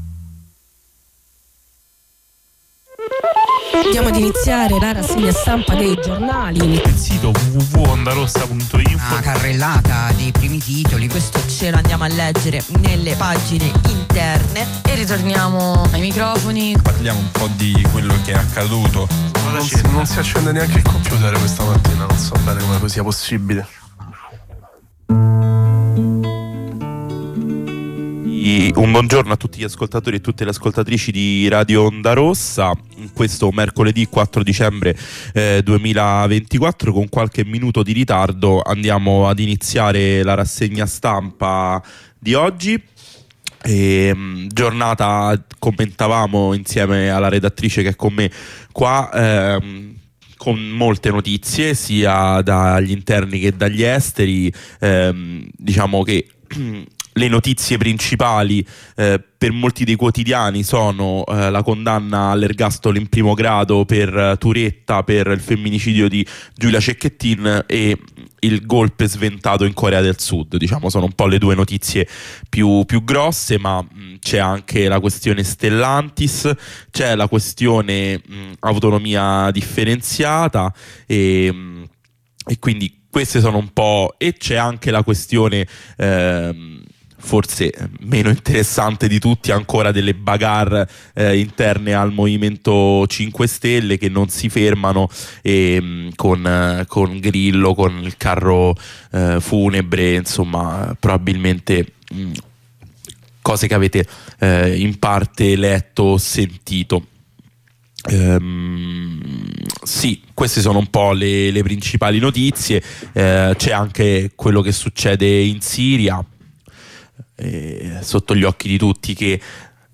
Lettura e commento dei quotidiani.
Rassegna stampa